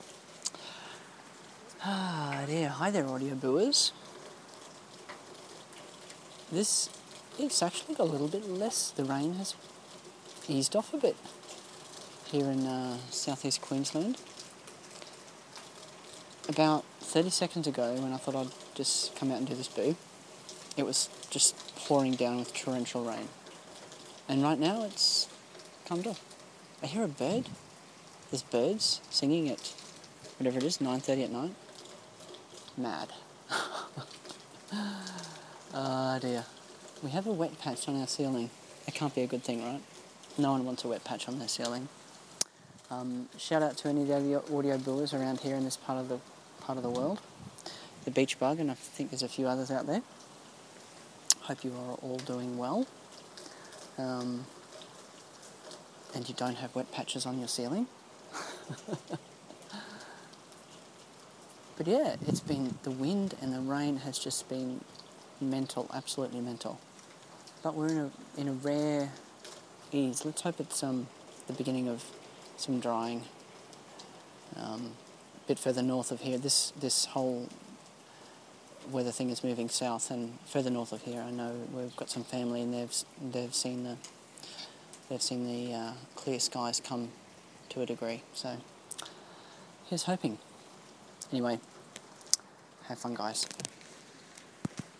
Rain, rain and more rain and wind...